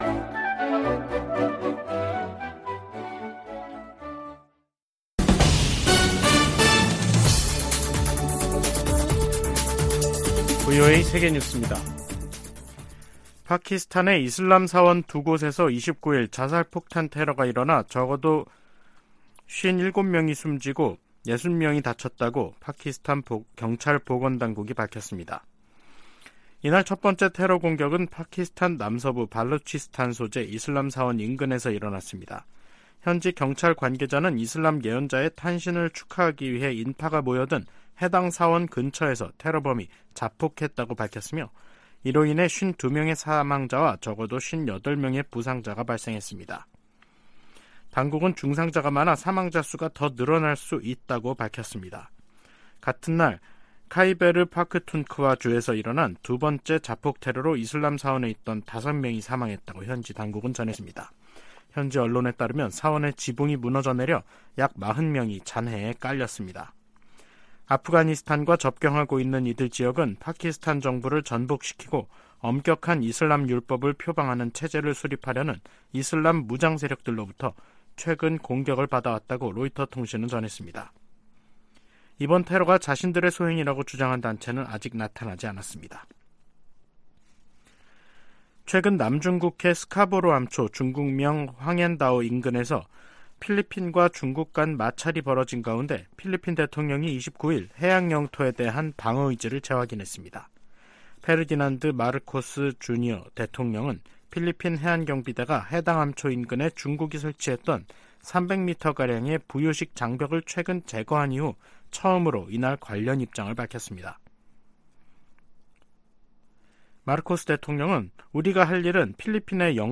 VOA 한국어 간판 뉴스 프로그램 '뉴스 투데이', 2023년 9월 29일 3부 방송입니다. 미 국무부는 북한의 핵무력 정책 헌법화를 비판하고, 평화의 실행 가능 경로는 외교뿐이라고 지적했습니다. 미 국방부는 북한이 전쟁의 어떤 단계에서도 핵무기를 사용할 수 있으며, 수천 톤에 달하는 화학 물질도 보유하고 있다고 밝혔습니다.